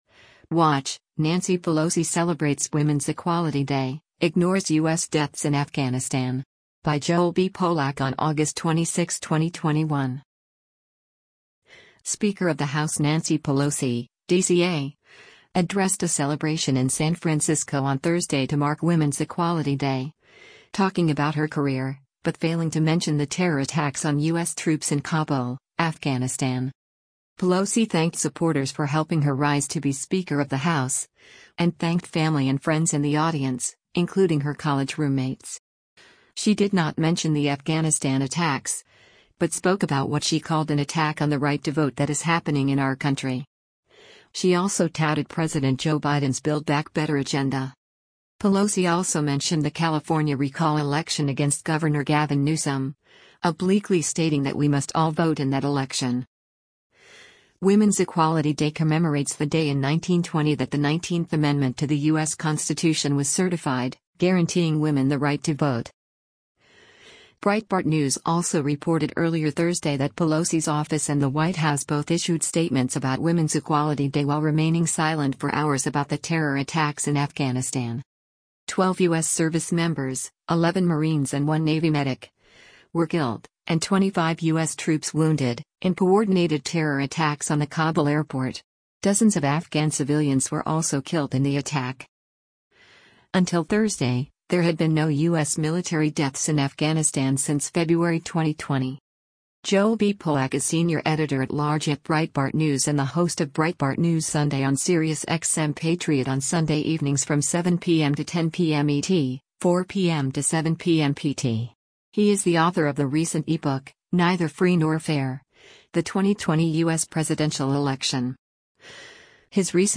Speaker of the House Nancy Pelosi (D-CA) addressed a celebration in San Francisco on Thursday to mark Women’s Equality Day, talking about her career, but failing to mention the terror attacks on U.S. troops in Kabul, Afghanistan.